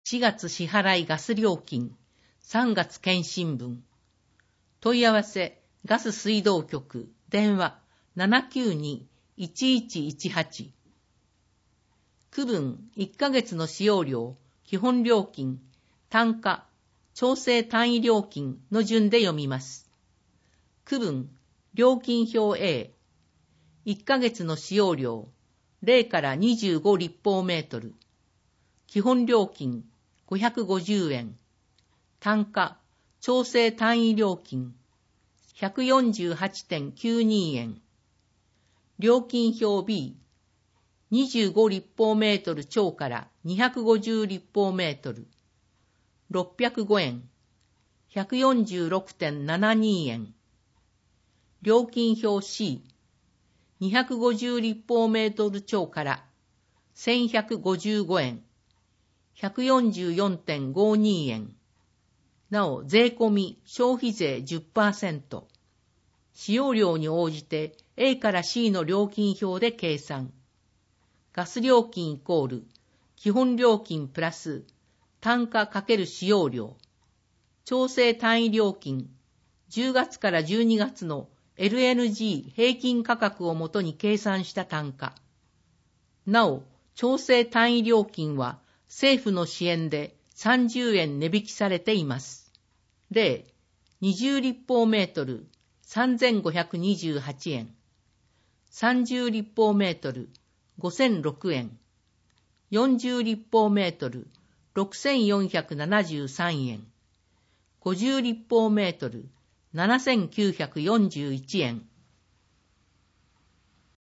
文字を読むことが困難な視覚障害者や高齢者や、市報を聞きたい方のために、「魚沼音声訳の会」のご協力により市報うおぬま音声版（ＭＰ3）をお届けします。